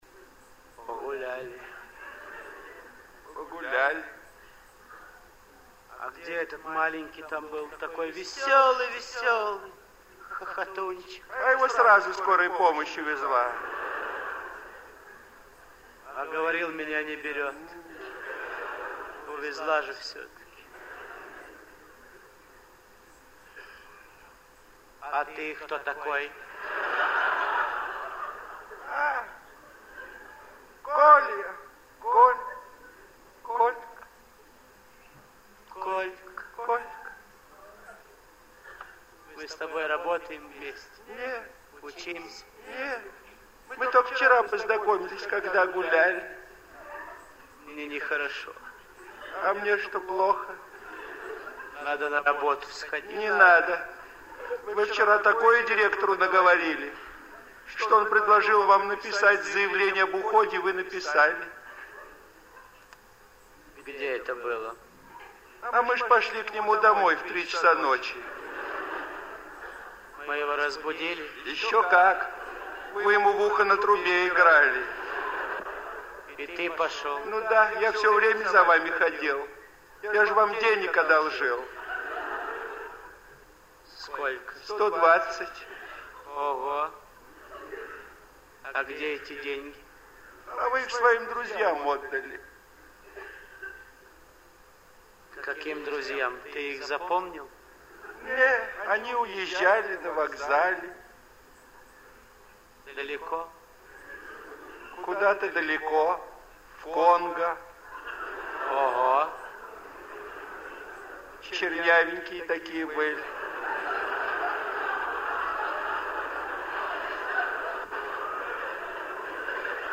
Продолжение редких записей миниатюр в исполнении Виктора Ильченко и Романа Карцева. 02 - В.Ильченко-Р.Карцев - Жду звонка